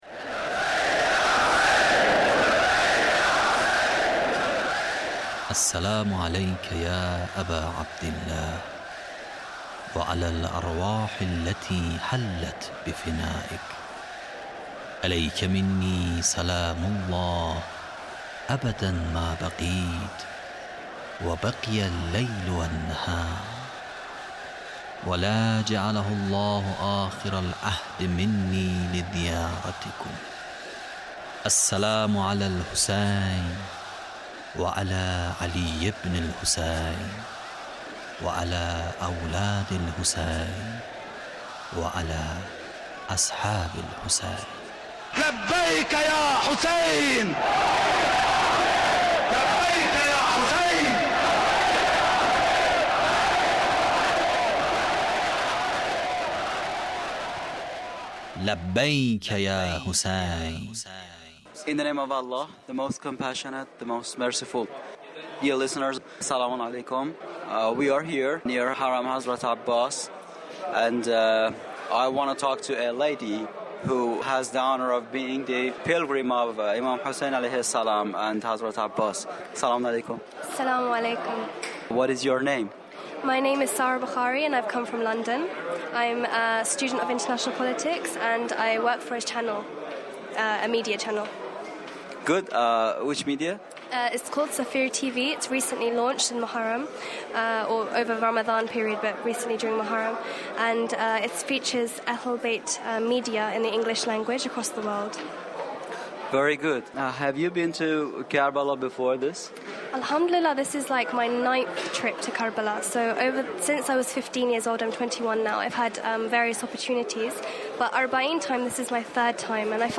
Interview with pilgrim of imam hussain (PART2)